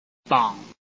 臺灣客語拼音學習網-客語聽讀拼-海陸腔-鼻尾韻
拼音查詢：【海陸腔】bong ~請點選不同聲調拼音聽聽看!(例字漢字部分屬參考性質)